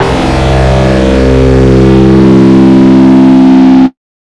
NYC125SYNT-R.wav